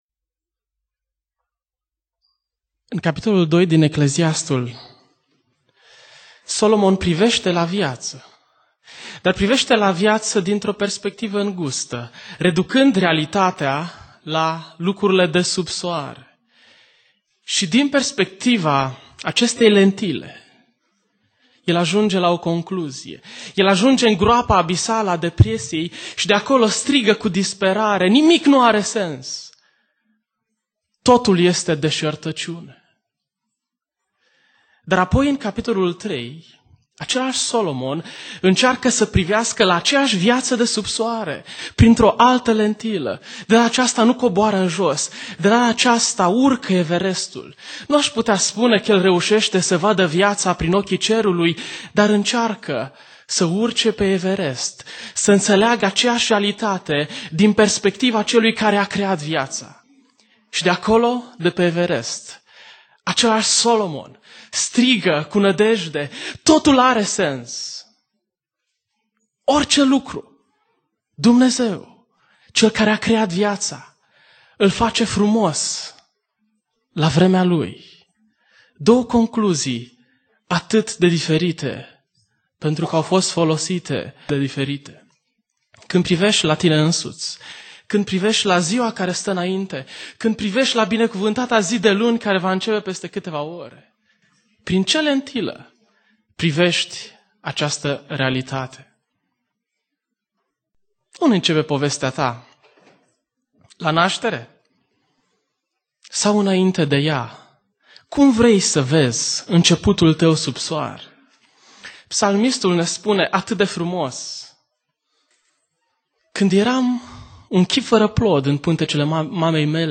Predica Aplicatie Eclesiastul cap. 3